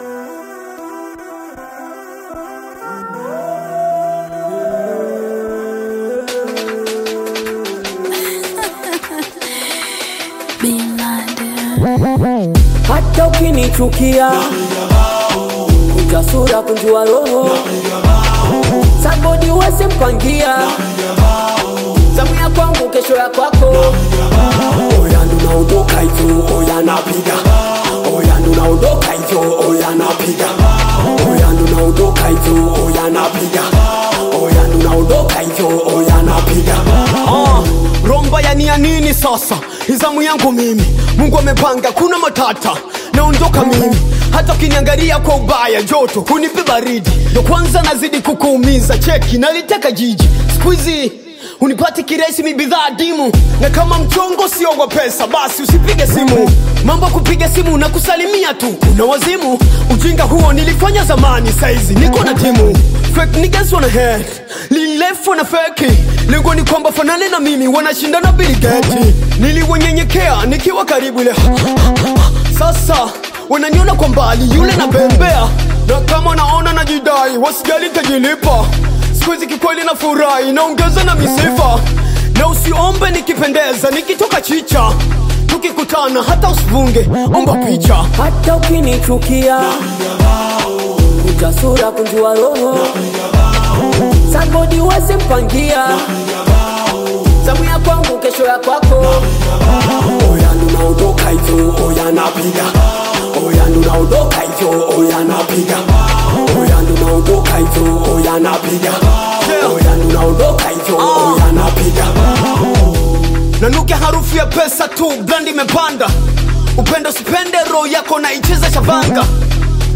hard-hitting Tanzanian Hip-Hop single
aggressive flow and street-inspired storytelling